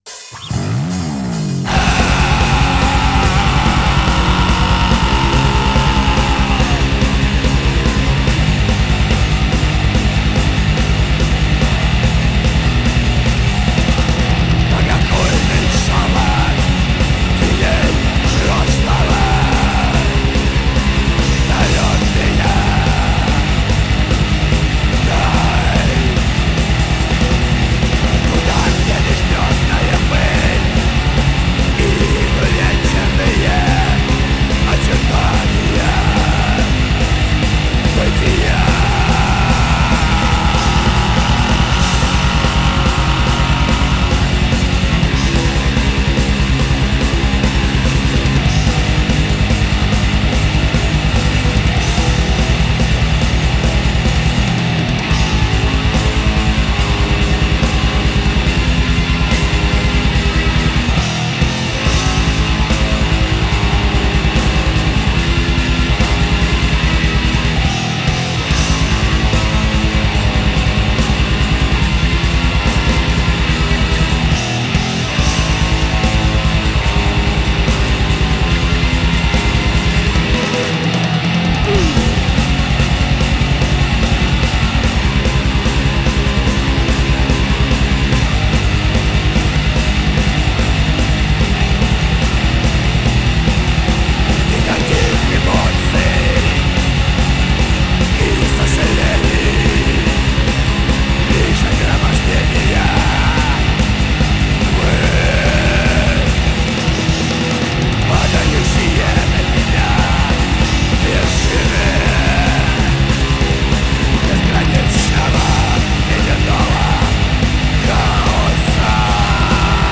European Black Metal